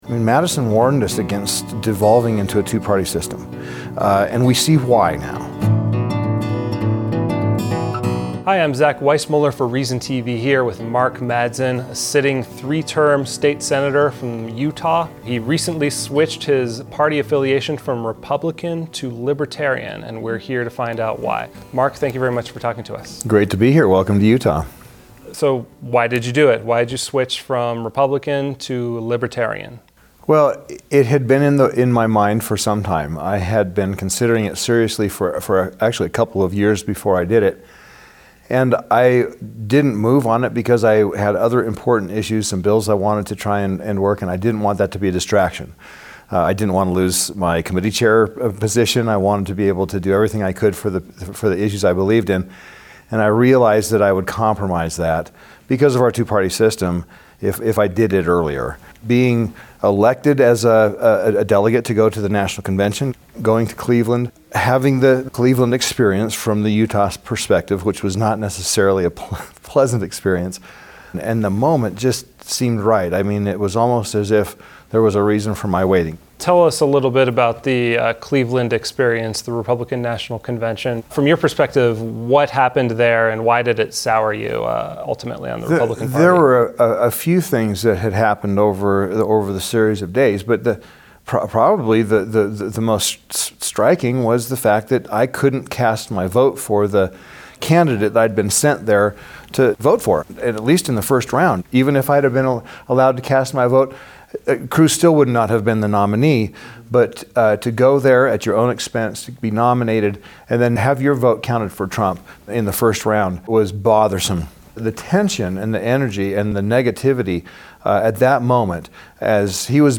Q&A with Senator Mark Madsen.
Reason TV sat down with Madsen in the chambers of Utah's capitol building in Salt Lake City to discuss more about what motivated his switch, what he hopes to accomplish as a Libertarian, and what the future of the Republican and Libertarian parties might look like.